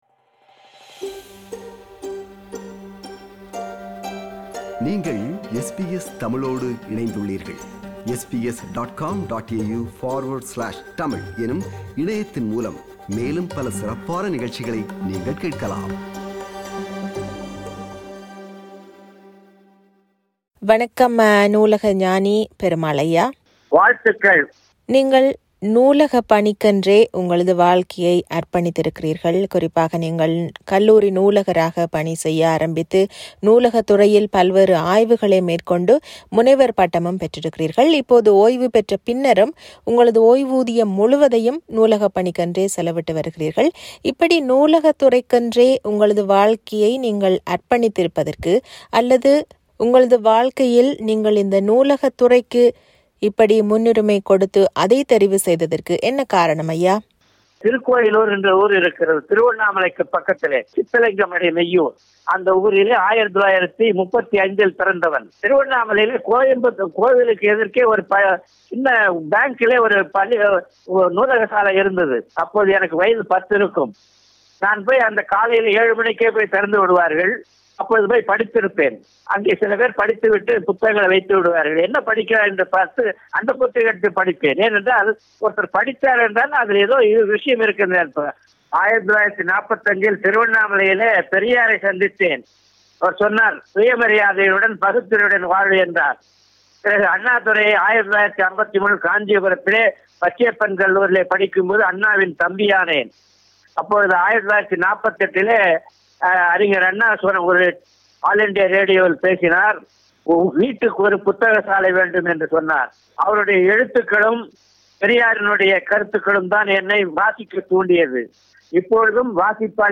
He advocates for school libraries and has a strong belief in the importance of libraries in schools for the creation of an educated society. This is an interview with him.